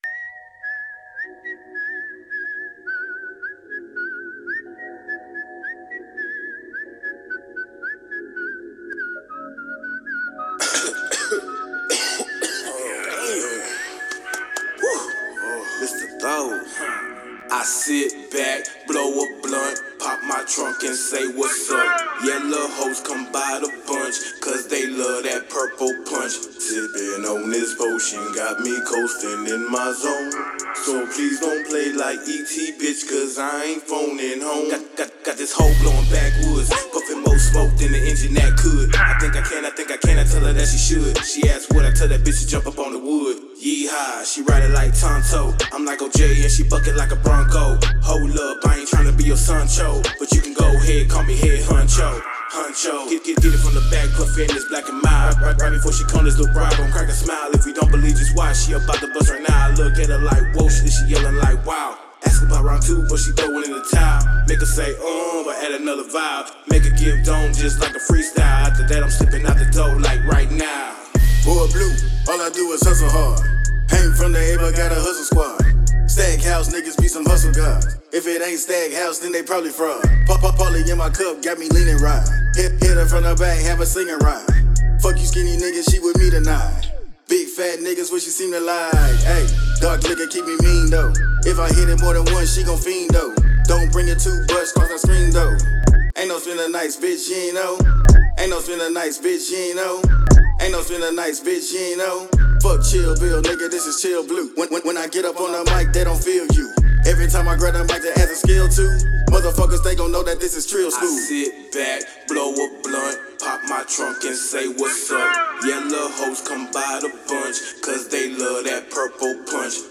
Hiphop
Description : FREESTYLE